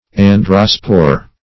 Search Result for " androspore" : The Collaborative International Dictionary of English v.0.48: Androspore \An"dro*spore\, n. [Gr.